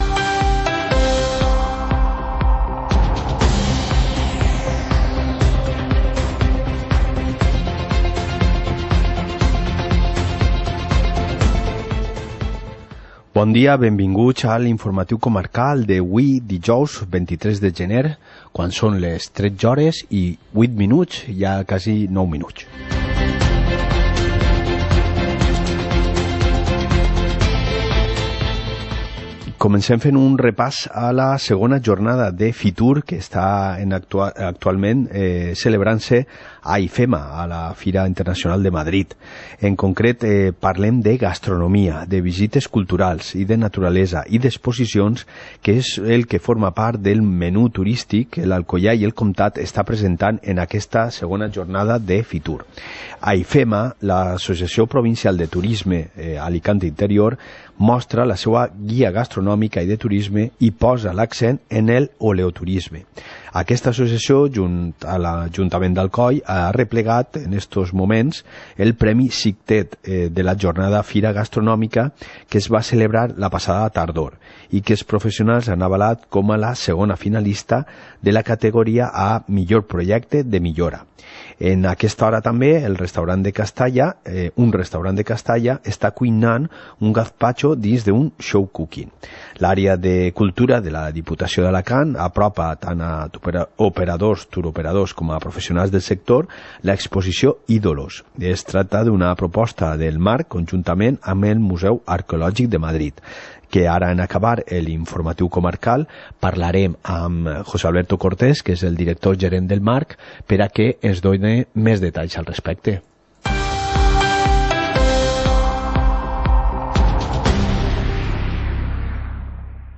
Informativo comarcal - jueves, 23 de enero de 2020